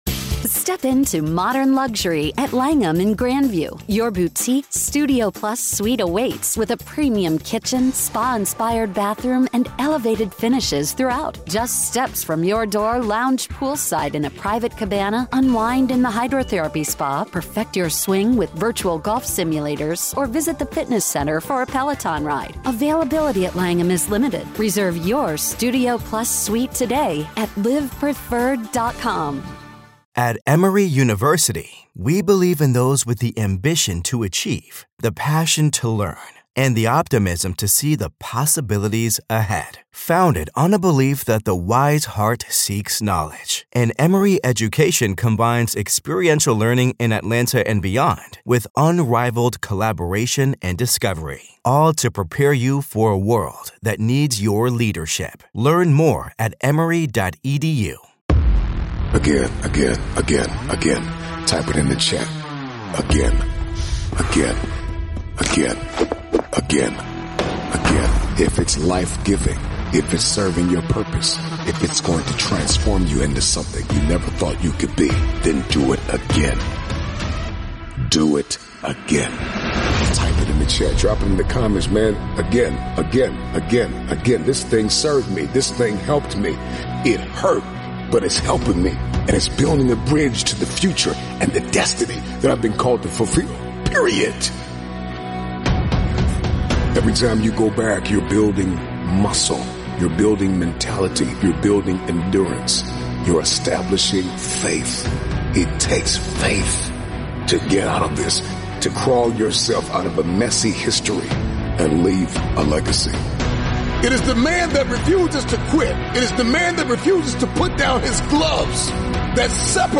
One of the motivational speeches